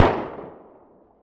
rocket_explosion.ogg